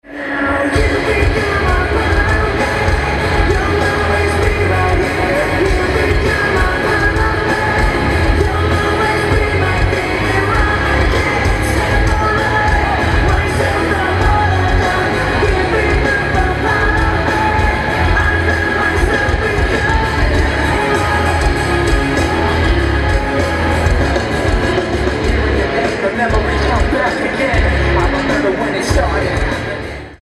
Venue:Pontiac Silverdome
Venue Type:Stadium